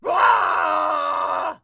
pigman_angry3.wav